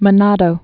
(mə-nädō)